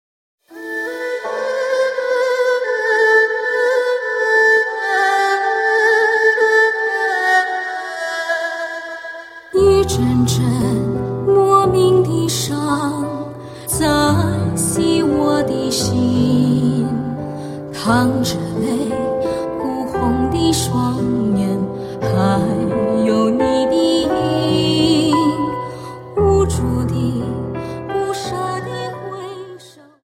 Slow Waltz 29 Song